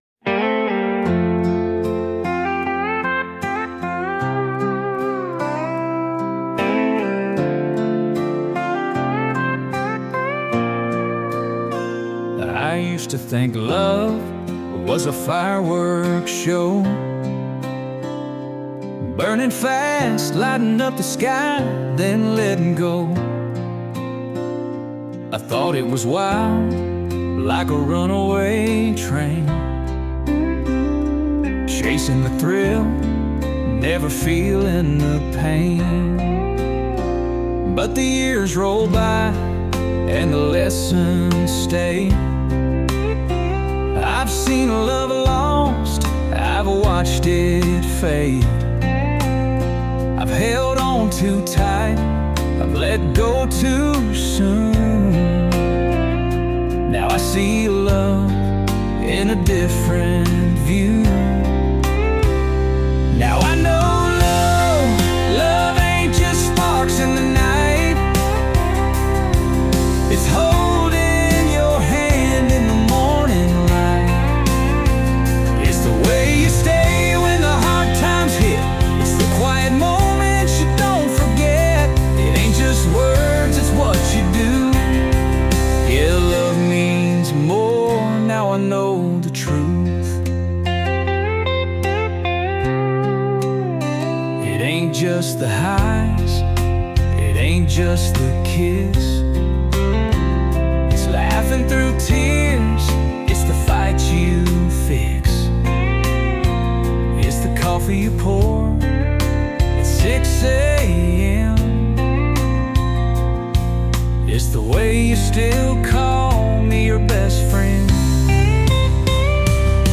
“Now I Know” is a well-crafted country ballad that has an appealing melody and a thoughtful lyric. The demo arrangement starts softly with mainly guitar and vocal, and then builds into a full-band production in the chorus. There is a strong, expressive male vocal performance, and the music tracks are well played.